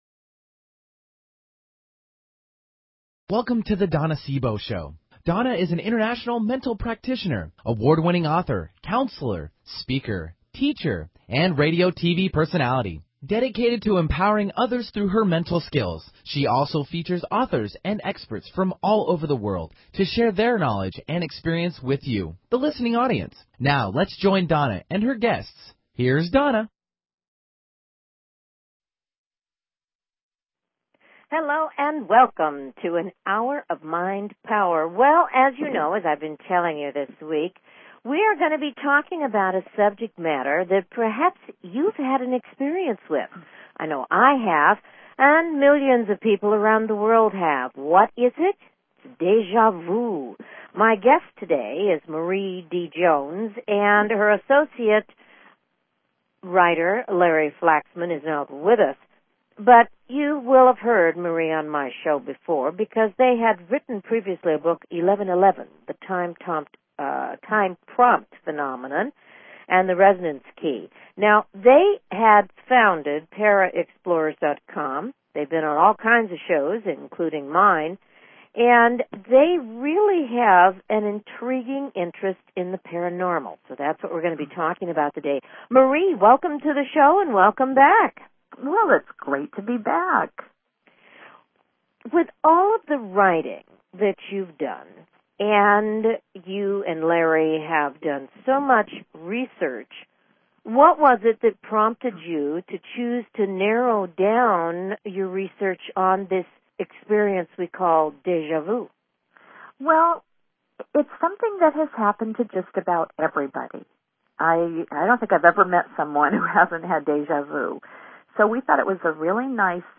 Her interviews embody a golden voice that shines with passion, purpose, sincerity and humor.
Talk Show
Tune in for an "Hour of Mind Power". Callers are welcome to call in for a live on air psychic reading during the second half hour of each show.